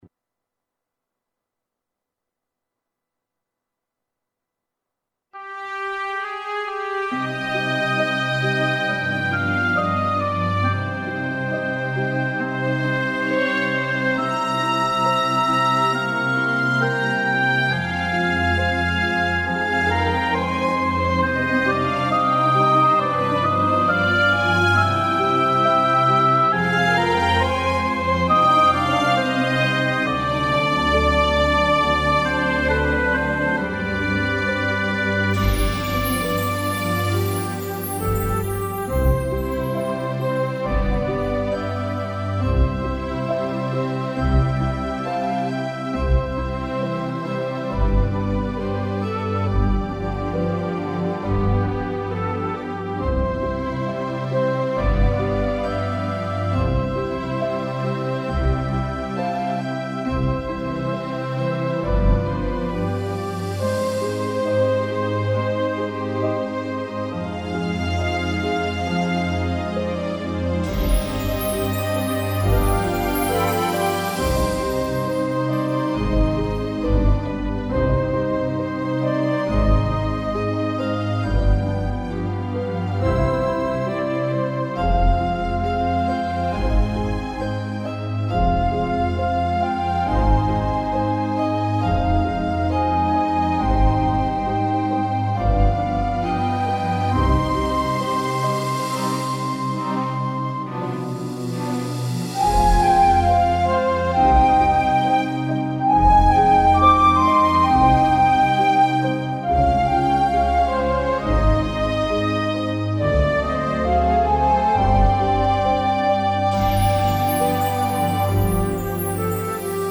•   Beat  03.
Cm T68) (Melody